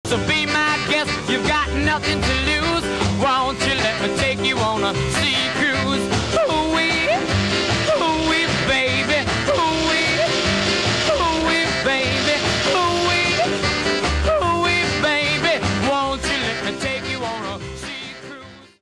the identical instrumental and sound effect track